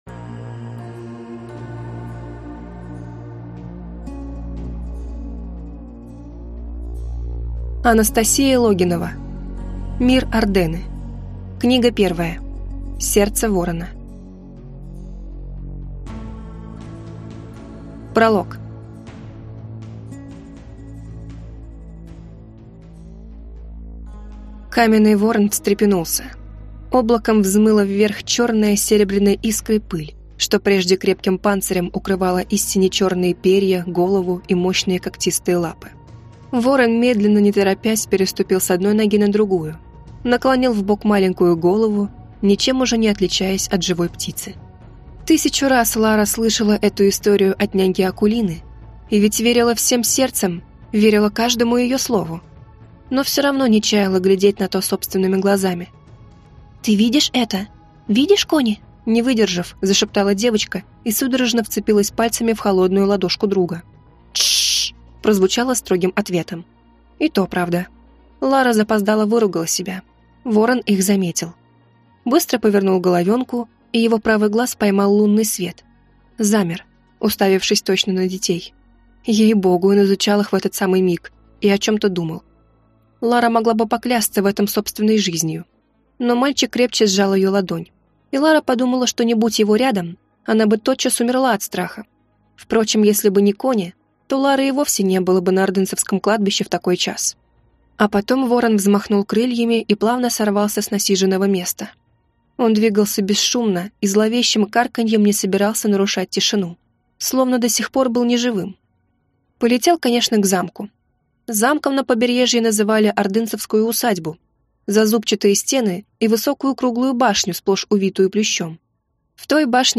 Аудиокнига Сердце ворона | Библиотека аудиокниг